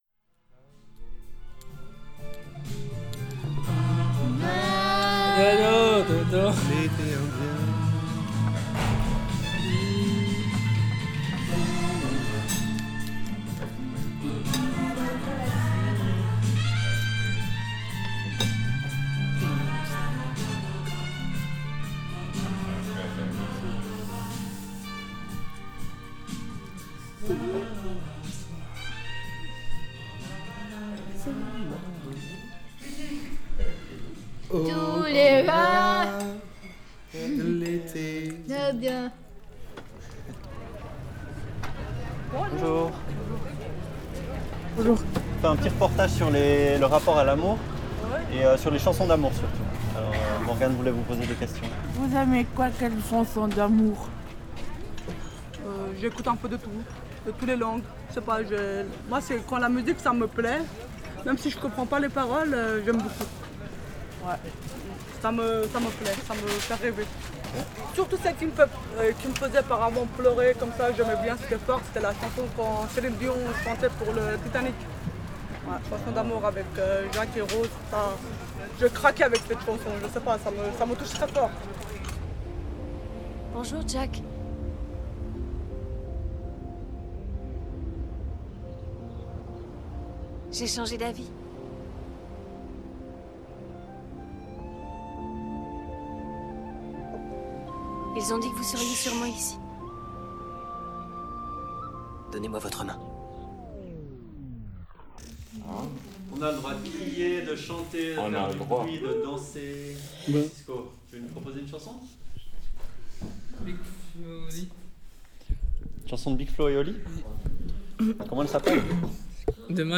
Portraits audios
Des participant-es témoignent de leur parcours, leurs aspirations, leurs difficultés, leur réalité et leurs rêves.